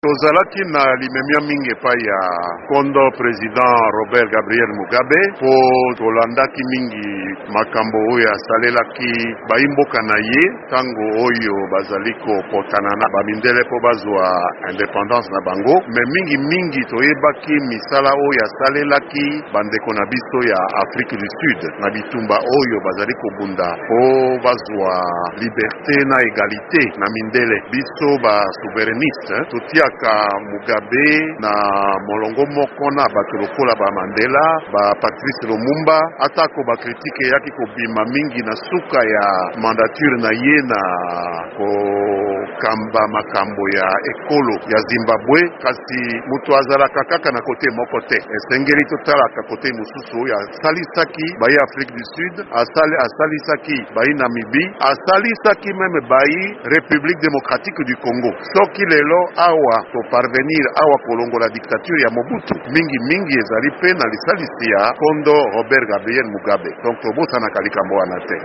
Liwa ya Mugabe : litatoli ya Lambert Mende